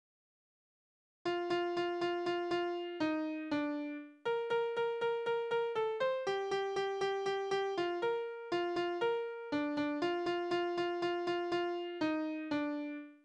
Balladen:
Tonart: B-Dur
Taktart: 3/4
Tonumfang: kleine Septime
Besetzung: vokal
Anmerkung: Vortragsbezeichnung: ziemlich schnell